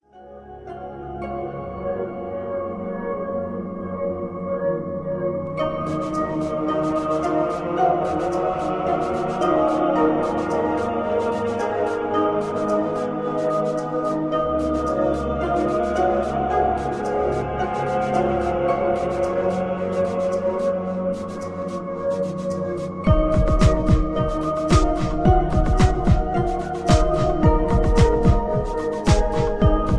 Klangstarker Electrotrack mit viel Melodie